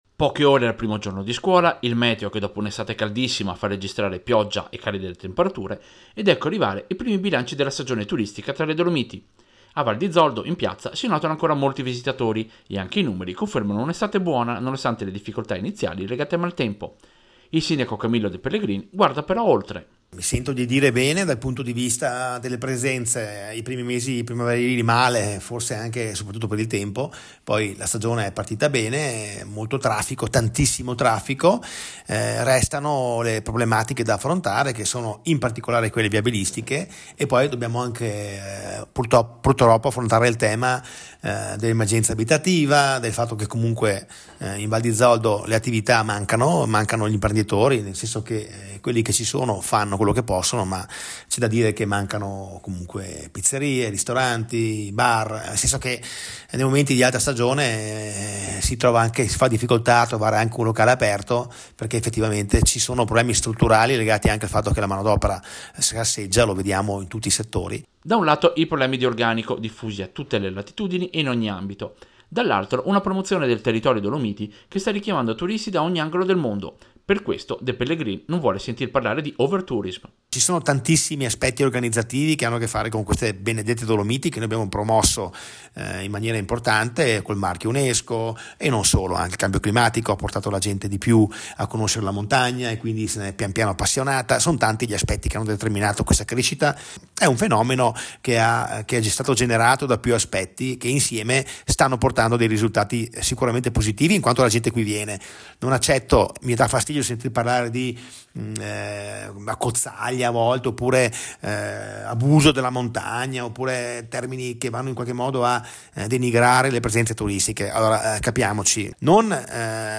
Servizio-Bilancio-turismo-Val-di-Zoldo-2024.mp3